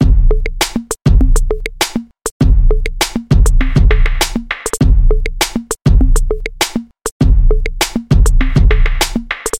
描述：HIP HOP RB CHILL
Tag: 100 bpm Hip Hop Loops Drum Loops 1.62 MB wav Key : Unknown